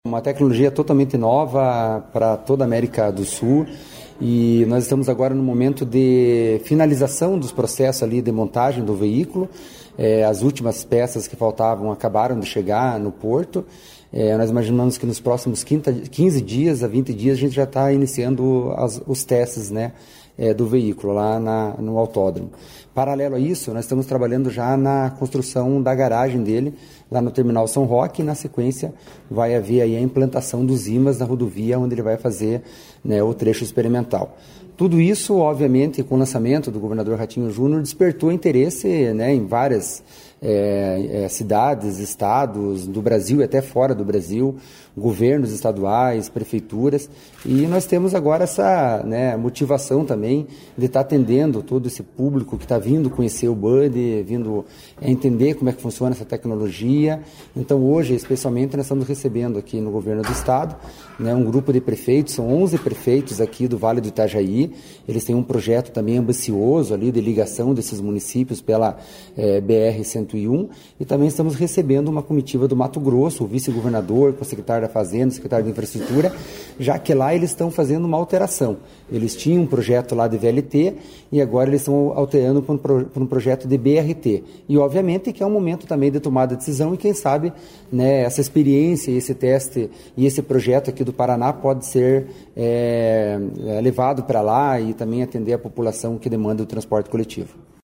Sonora do diretor-presidente da Amep, Gilson Santos, sobre a apresentação do Bonde Urbano Digital a autoridades de Santa Catarina e do Mato Grosso